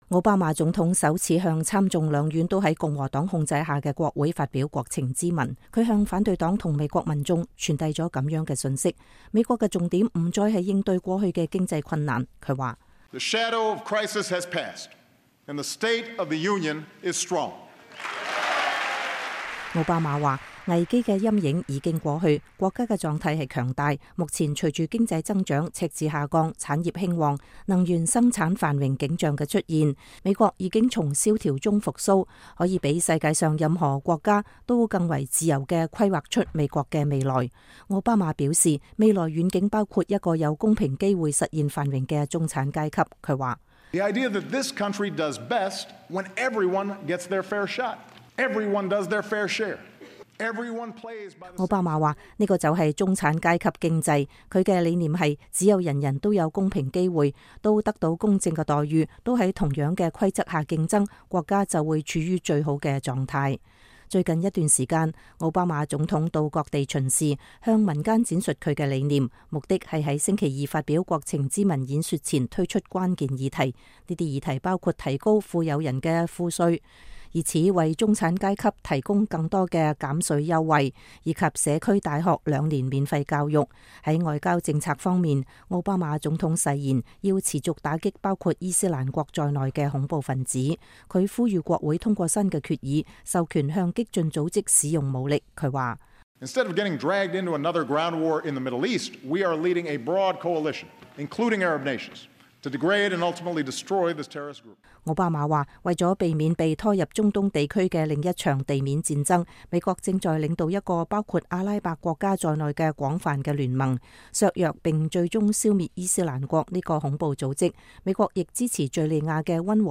2015-01-21 美國之音視頻新聞: 奧巴馬國情咨文重點觸及經濟復甦